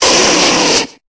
Cri d'Arcanin dans Pokémon Épée et Bouclier.